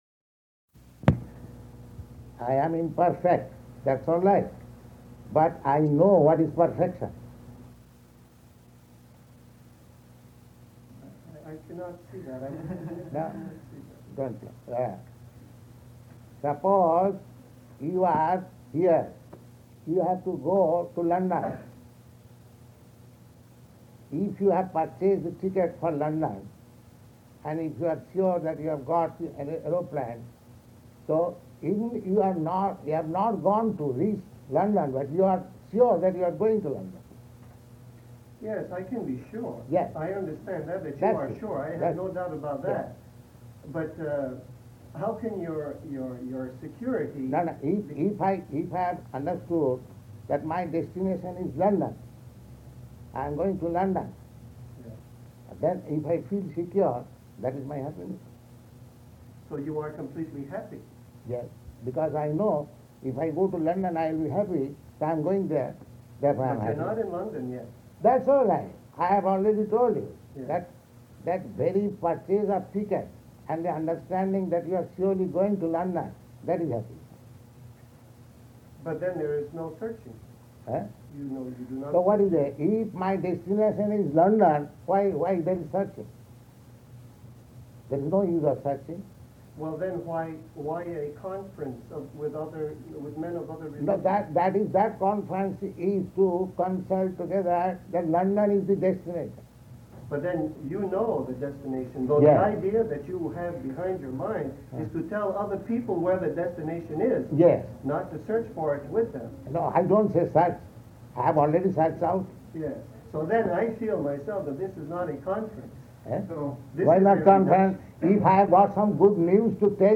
Conversation with Religious Group
Location: Montreal